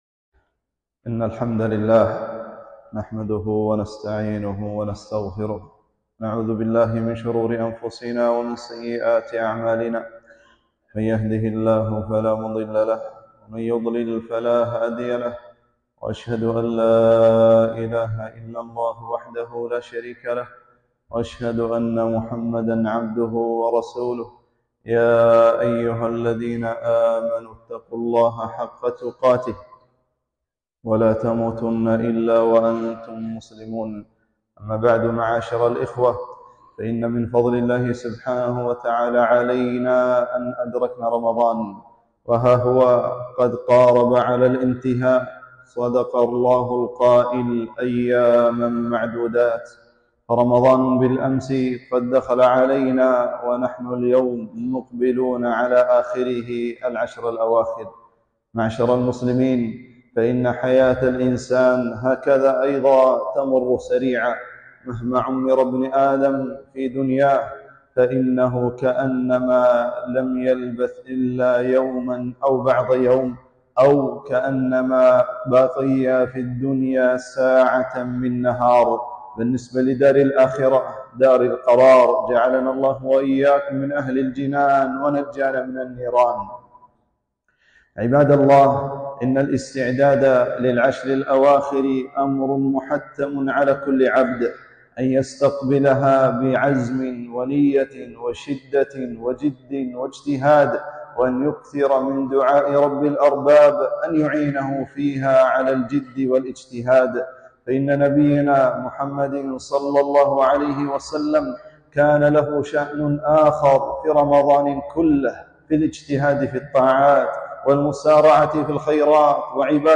خطبة - استقبال العشر الأواخر